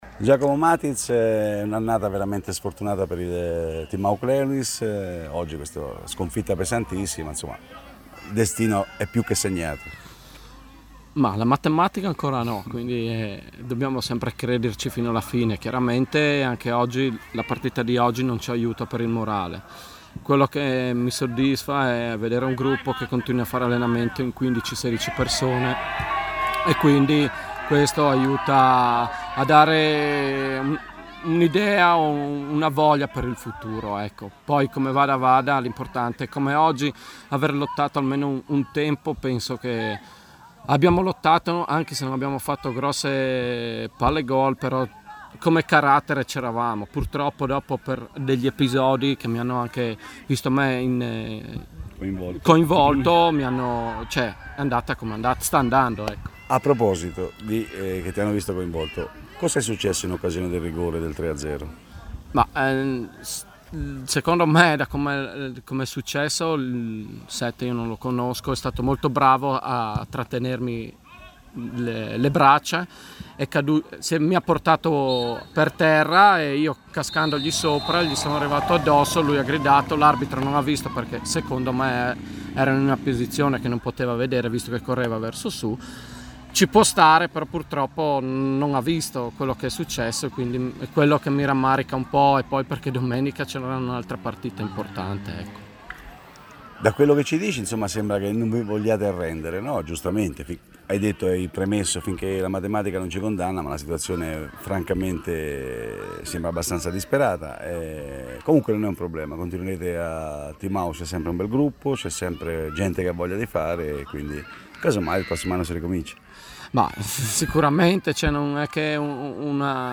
Proponiamo le interviste realizzate ieri al termine dell’anticipo di Seconda Categoria fra Timaucleulis e Illegiana, concluso con un netto 6-0 per gli ospiti.